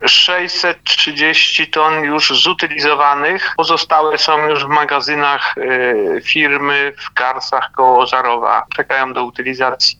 Łącznie w składowisku znajdowało się ich 1230 ton, mówi Robert Fidos, wójt gminy: